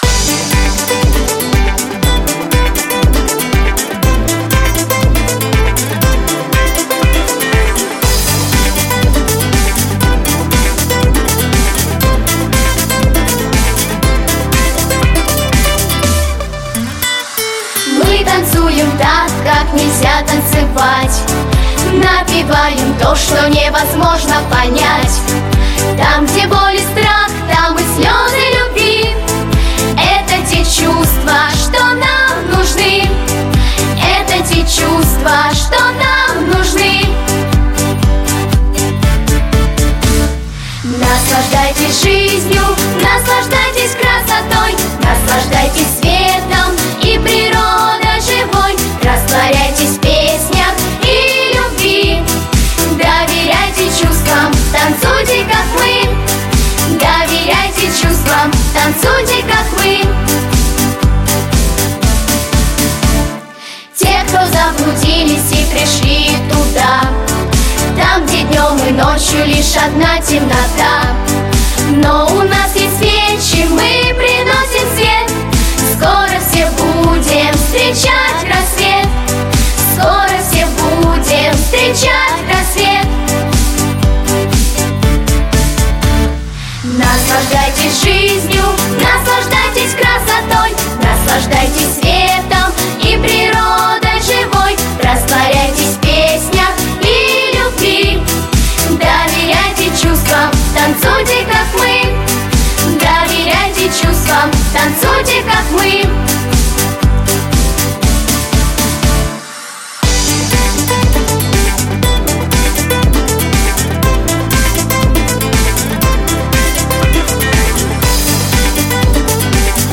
• Качество: Хорошее
• Жанр: Детские песни
Детская вокально-эстрадная студия